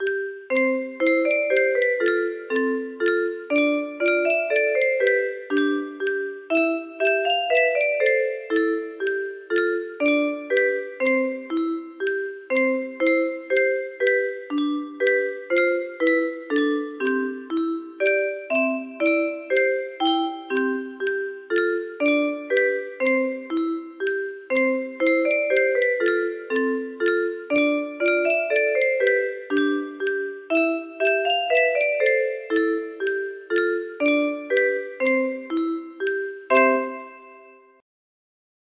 We wish you a merry christmas music box melody